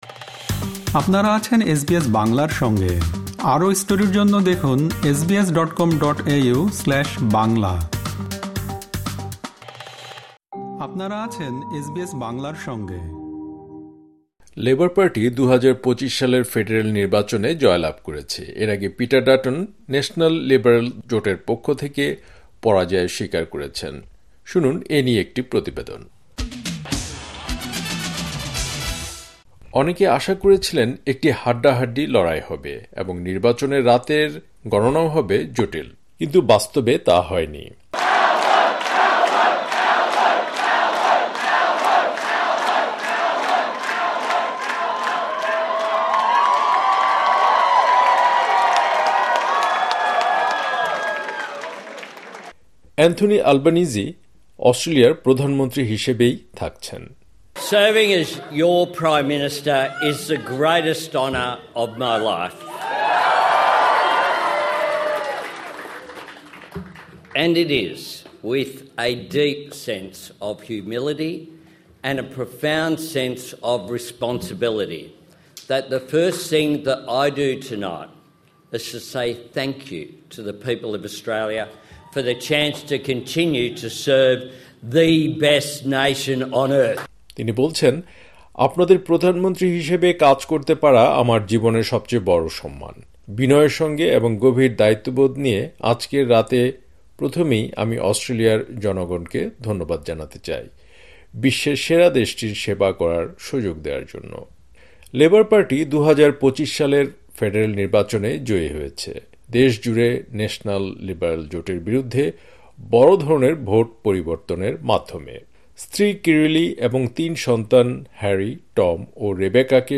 লেবার পার্টি ২০২৫ সালের ফেডারেল নির্বাচনে জয়লাভ করেছে। এর আগে পিটার ডাটন ন্যাশনাল - লিবারেল জোটের পক্ষ থেকে পরাজয় স্বীকার করেছেন। শুনুন এ নিয়ে একটি প্রতিবেদন।